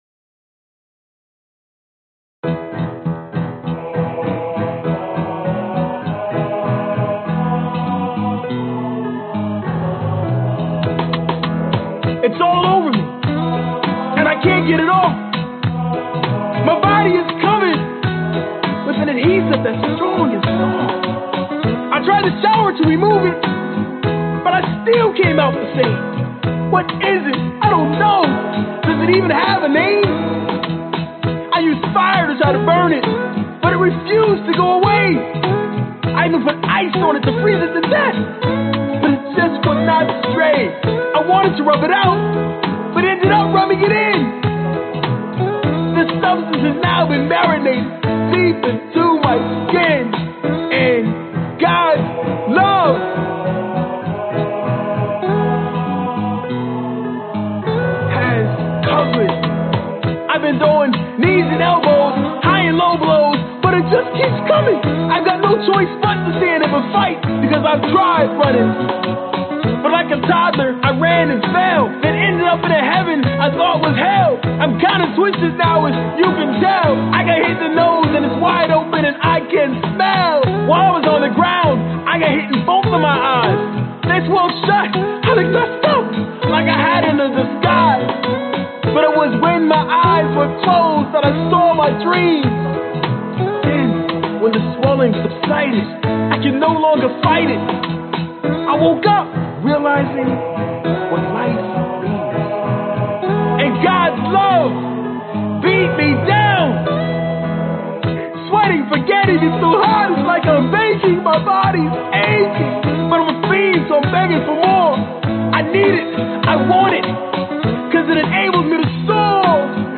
描述：又是一个宗教混音。
Tag: 福音 教堂 宗教 合唱团 吉他 贝斯 钢琴